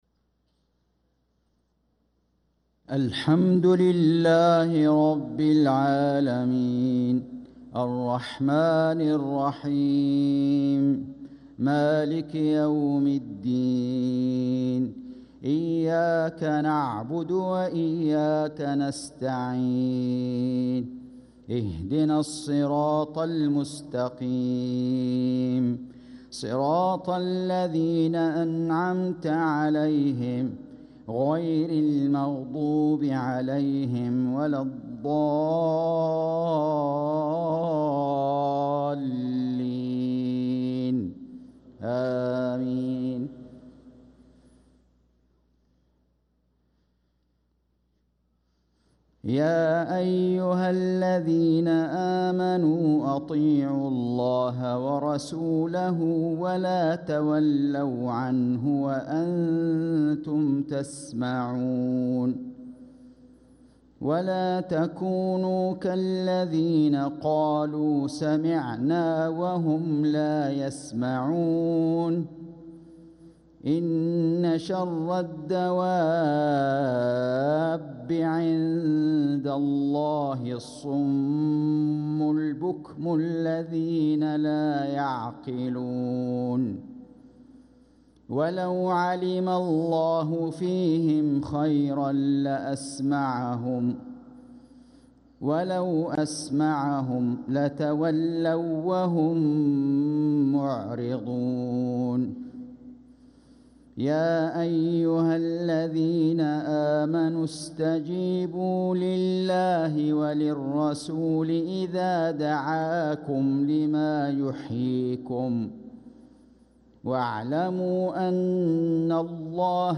صلاة العشاء للقارئ فيصل غزاوي 4 جمادي الأول 1446 هـ
تِلَاوَات الْحَرَمَيْن .